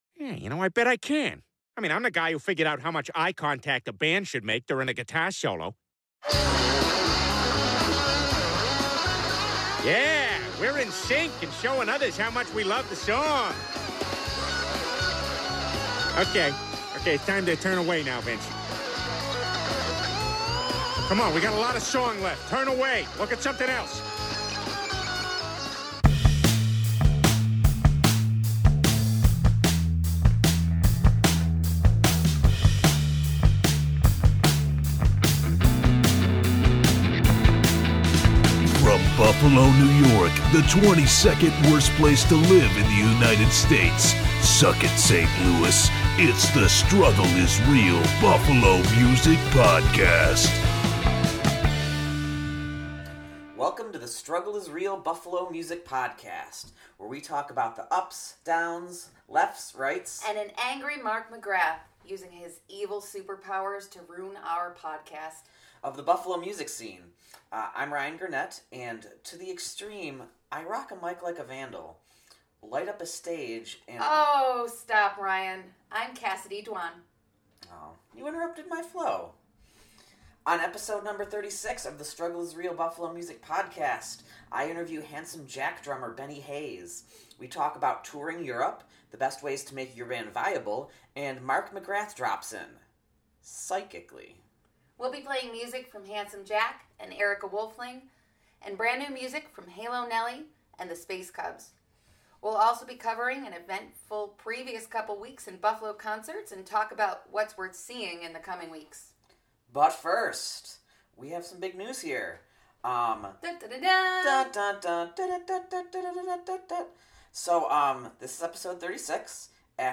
49:26 — Our recorder dies here, but our editor does such a great job that you can’t tell, but we start making jokes about it.